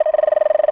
cartoon_electronic_computer_code_06.wav